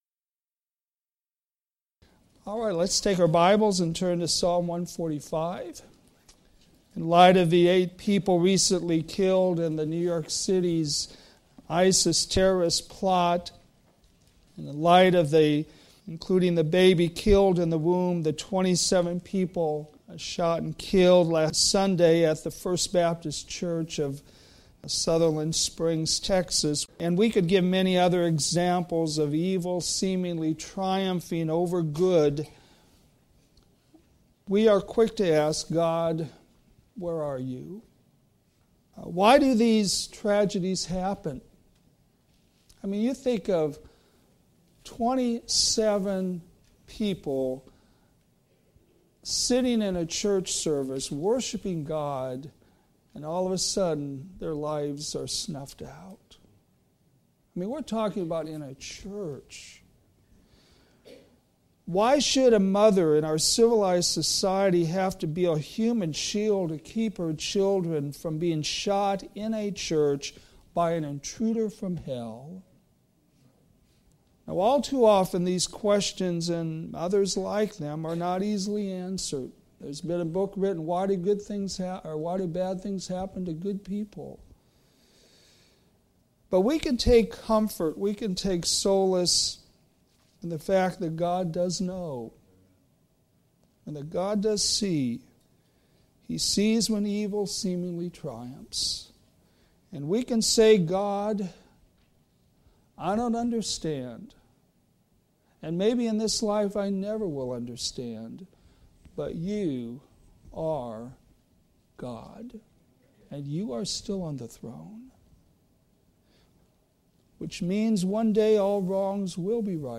All Sermons - Westside Baptist Church